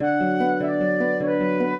flute-harp
minuet4-8.wav